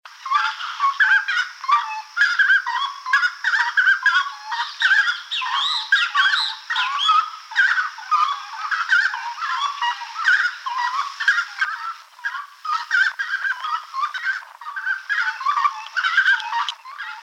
Chiricote (Aramides cajaneus)
Nombre en inglés: Grey-cowled Wood Rail
Localidad o área protegida: Reserva Ecológica Costanera Sur (RECS)
Condición: Silvestre
Certeza: Fotografiada, Vocalización Grabada
Recs.Chiricotes.mp3